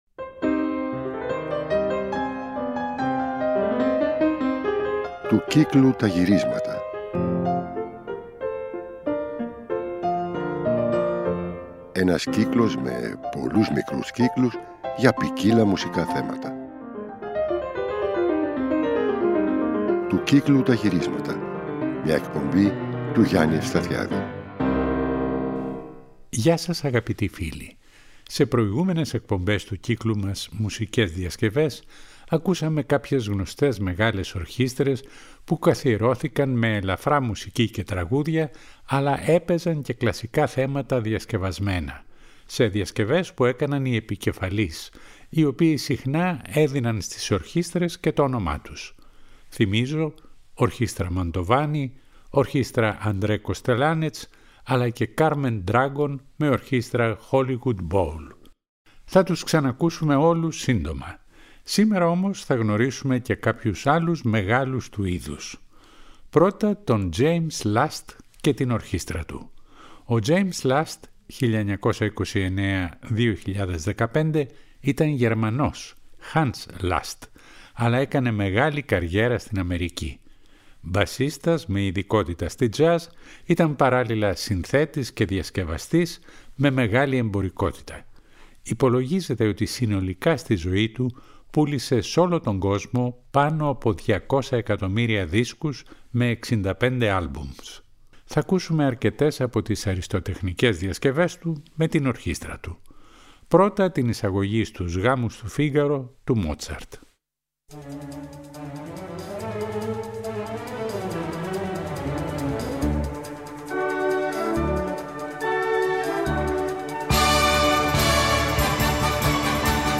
Σ’ αυτήν την εκπομπή του κύκλου μας «Μουσικές Διασκευές» θα μείνουμε σε μεγάλες ορχήστρες που έπαιζαν κλασσικές διασκευές.